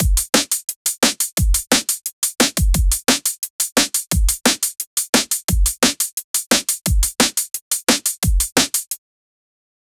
drum loop in "drum & bass" genre include kick snare hi hats cymbals, 175 bpm, like noisia, fred again, camo & krooked, sub focus artists. a quality, varied, full-fledged drum loop that feels like live drums 0:10 Created Apr 28, 2025 1:34 PM
drum-loop-in-drum--iz6zqupy.wav